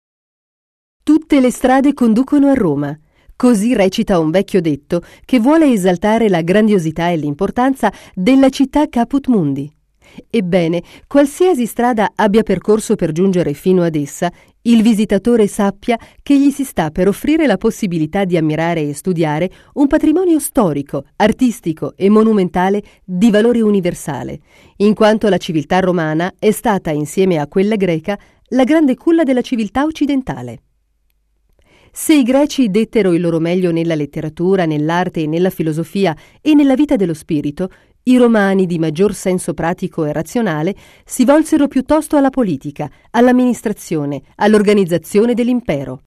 Sprecherin italienisch. voce narrante nazionale per RAI, SKY, audiolibri, audioguide
Sprechprobe: Industrie (Muttersprache):
female italian voice over artist. voce narrante nazionale per RAI, SKY, audiolibri, audioguide